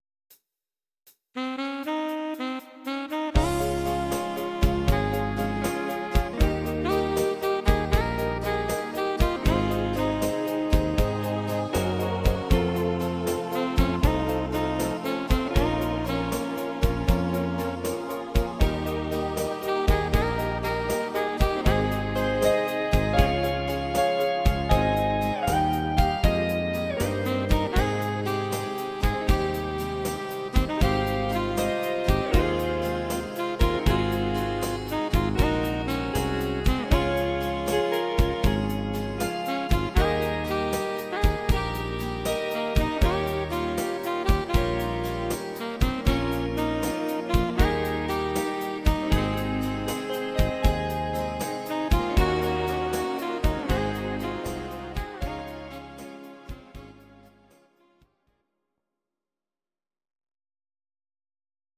Audio Recordings based on Midi-files
Pop, Medleys